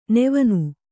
♪ nevanu